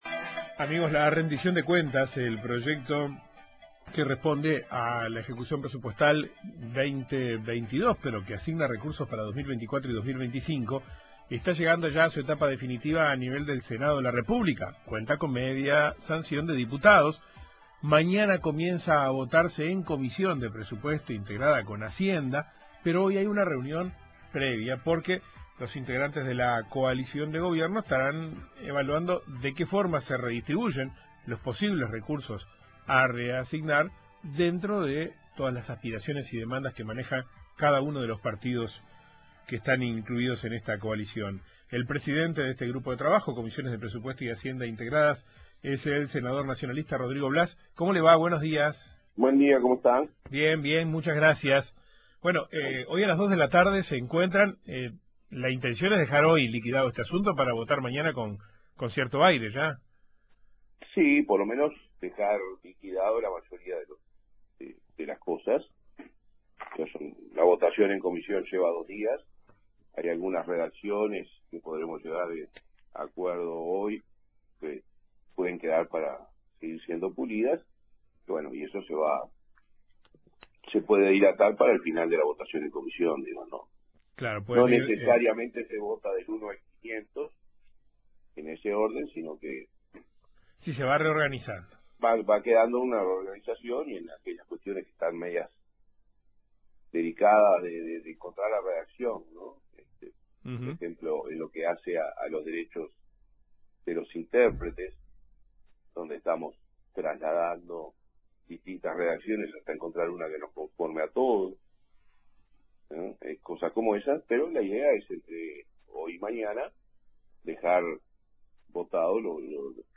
Rodrigo Blas, presidente de la comisión de Presupuesto y Hacienda integradas del Senado, dijo que hay pedidos estimados en 12 millones de pesos, además de los aumentos para militares